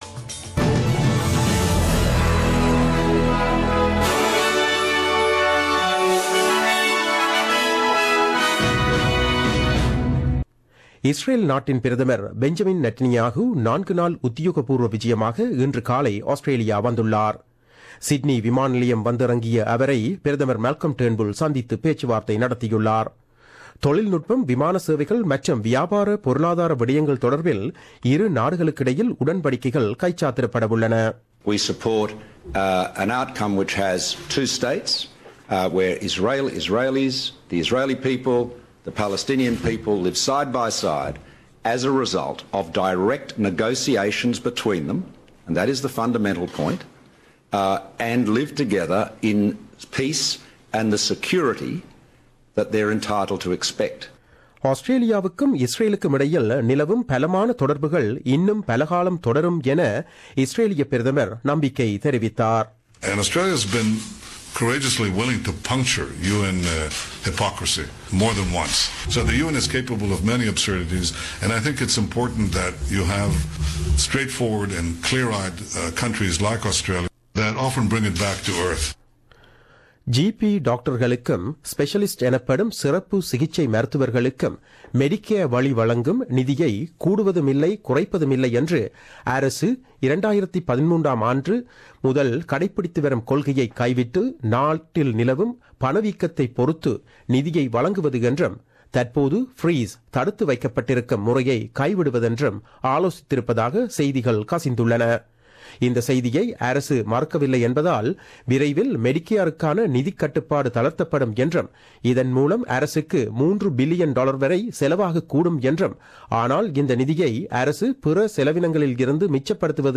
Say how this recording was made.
The news bulletin aired on 22 February 2017 at 8pm.